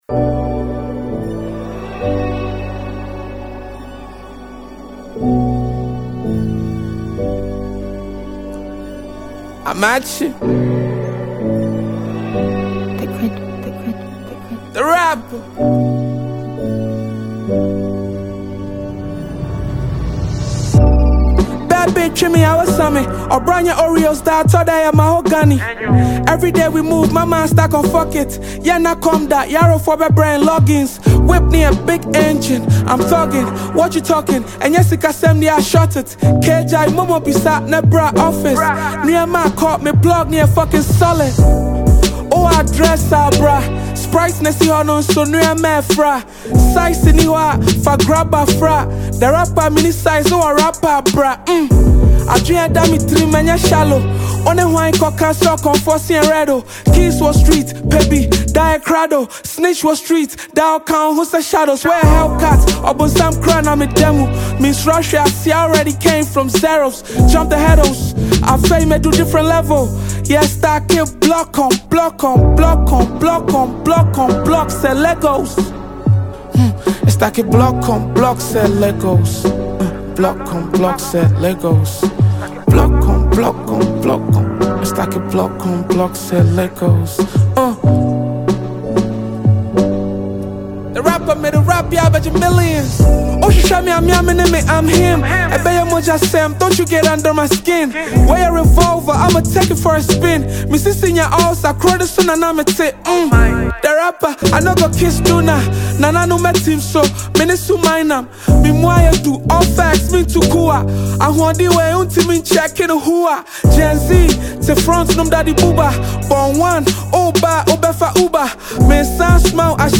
Ghana Afrobeat MP3